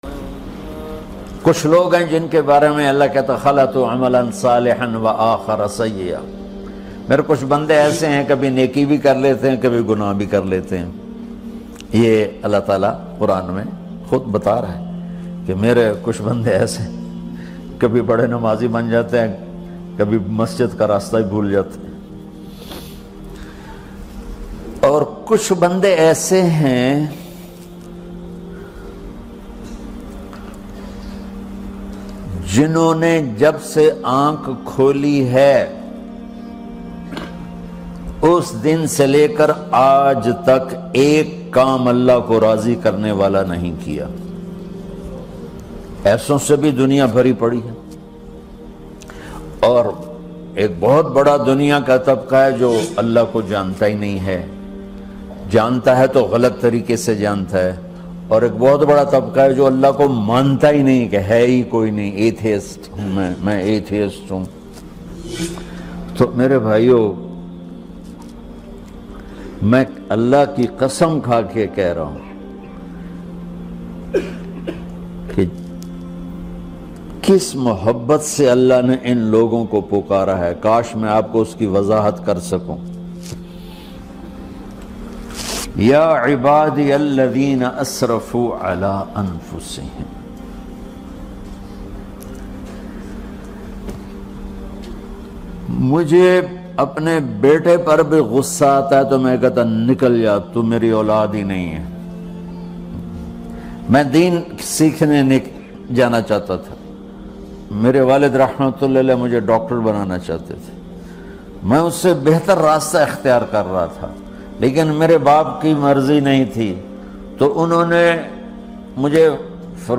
Allah Ko Kis Banday Se Mohabat Hai – Bayaan by Maulana Tariq Jameel
Maulana Tariq Jameel is regarded as one of the greatest Islamic preachers of our times.
Allah-Ko-Kis-Banday-Se-Mohabat-Hai--Jameel-Full-Bayaan.mp3